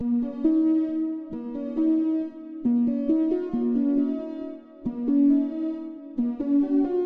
Tag: 136 bpm Trap Loops Synth Loops 1.19 MB wav Key : Unknown Logic Pro